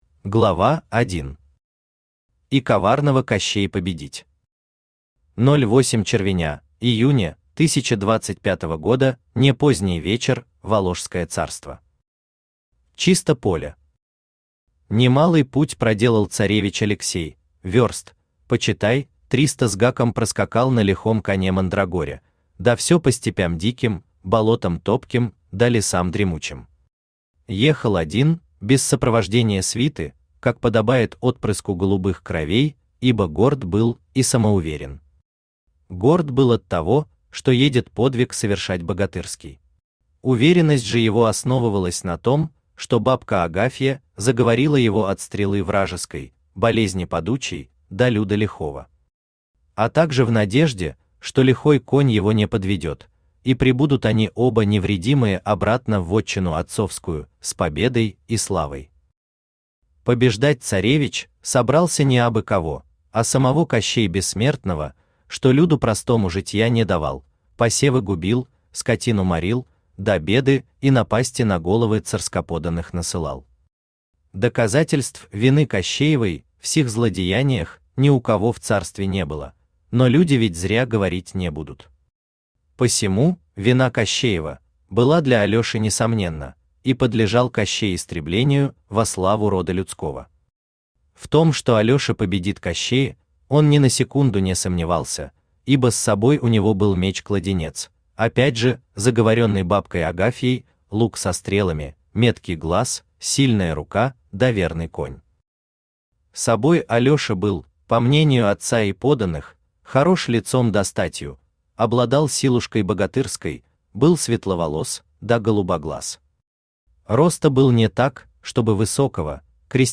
Аудиокнига Сказ про Кощея | Библиотека аудиокниг
Aудиокнига Сказ про Кощея Автор Андрей Евгеньевич Лукаш Читает аудиокнигу Авточтец ЛитРес.